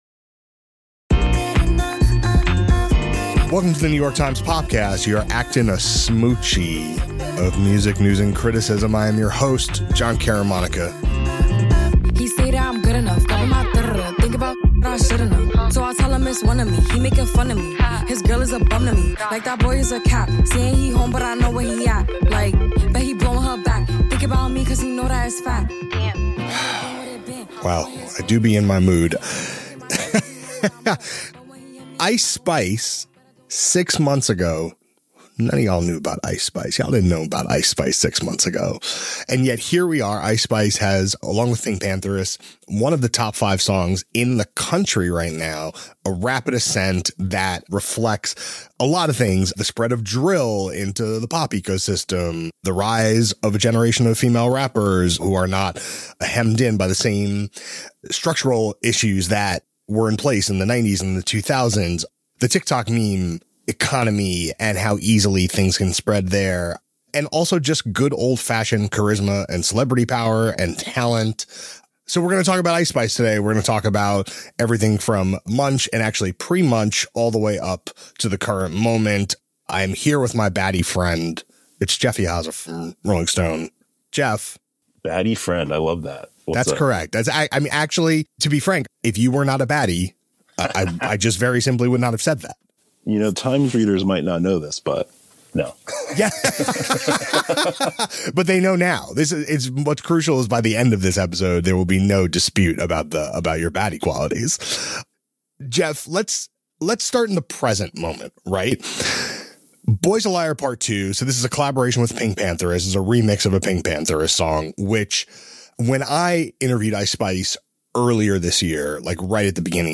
A conversation about the ubiquitous Bronx rapper’s rise, and her boosts from the meme universe.